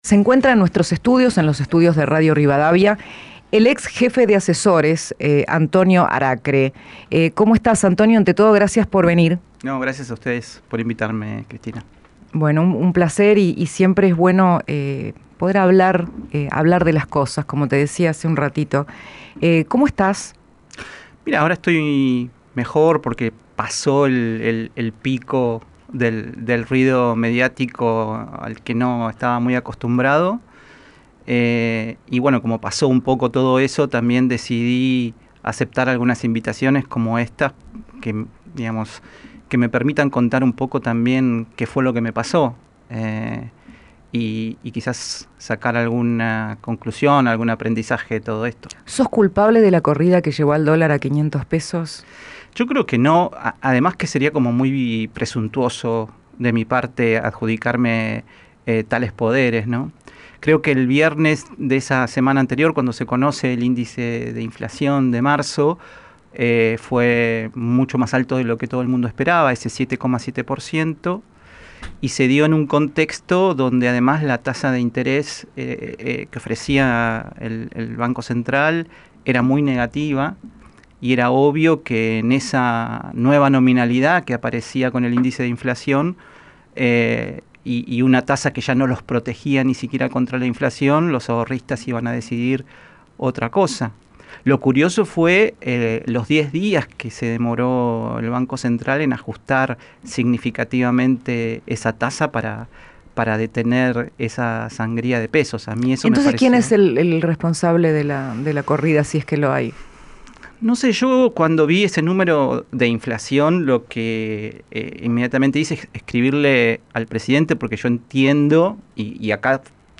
'Editaron el documento y le pasaron a algunos periodistas copias donde decía que mi propuesta era devaluar un 60%', explicó el ex jefe de Asesores del Presidente en una entrevista con Cristina Pérez.